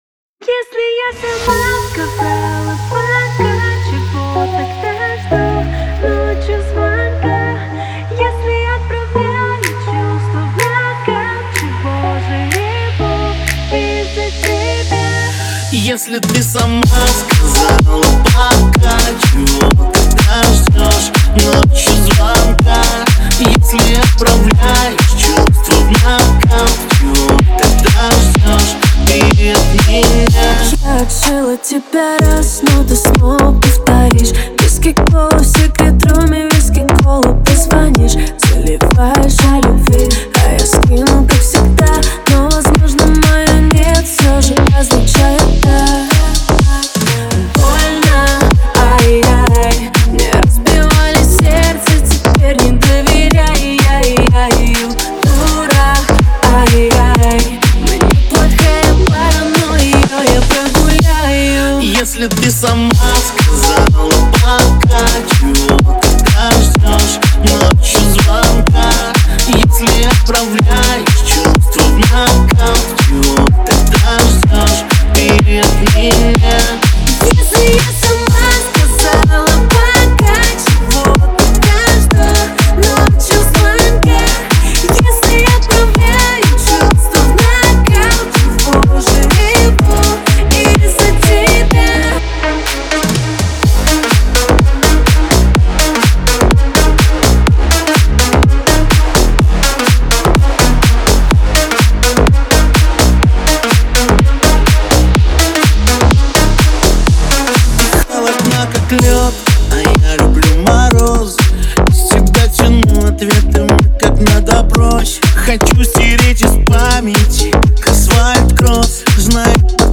Трек размещён в разделе Русские песни.